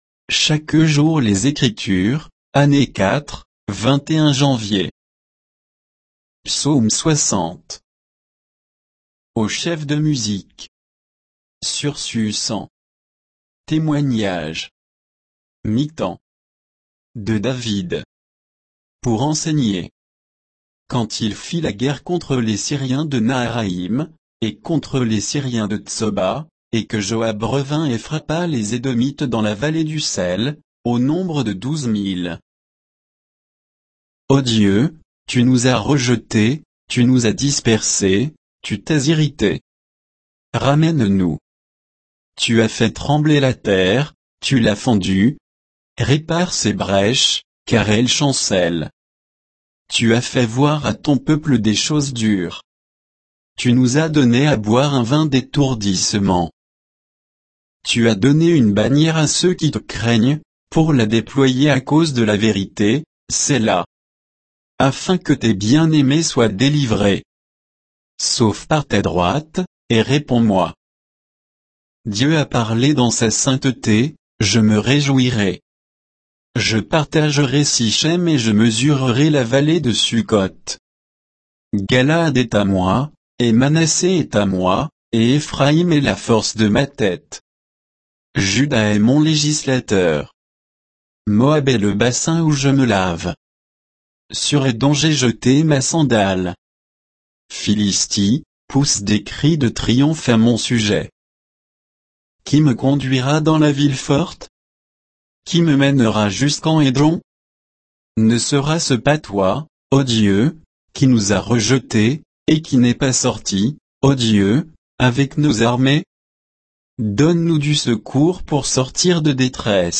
Méditation quoditienne de Chaque jour les Écritures sur Psaume 60